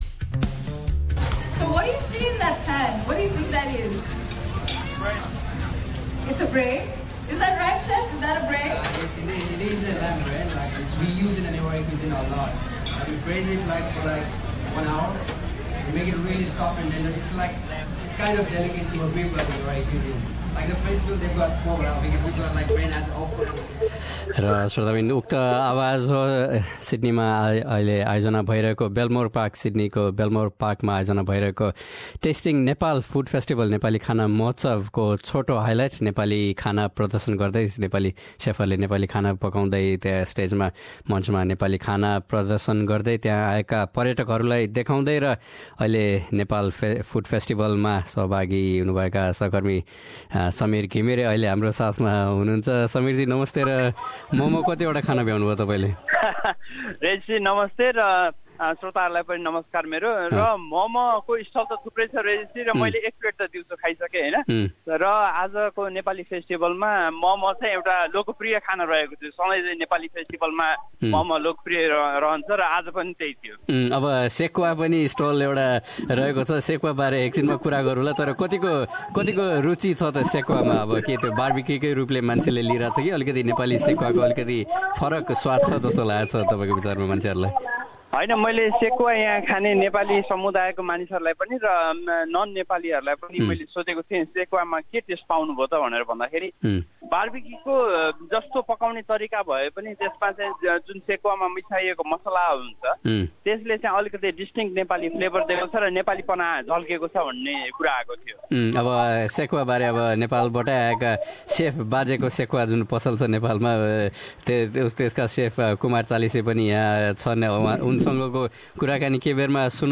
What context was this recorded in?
Tasting Nepal- Nepali food festival Sydney, April 8 2017.